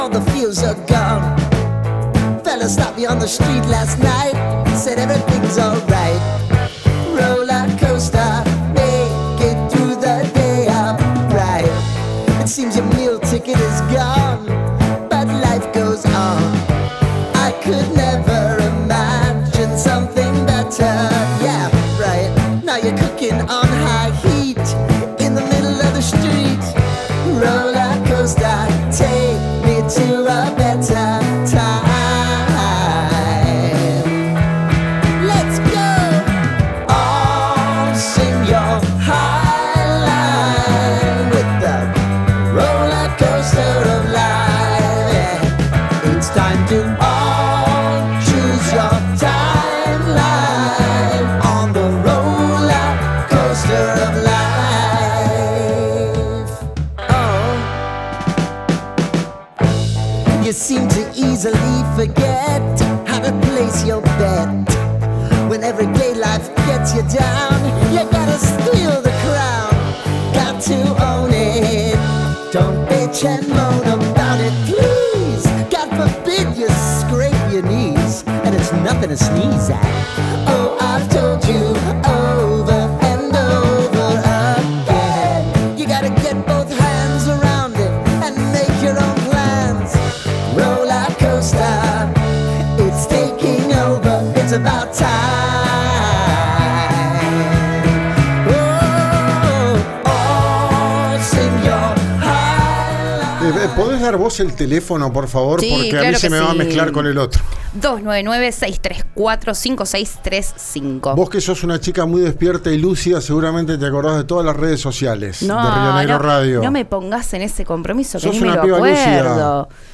Escuchá a Leticia Esteves, secretaria de Ambiente de Neuquén, por RÍO NEGRO RADIO: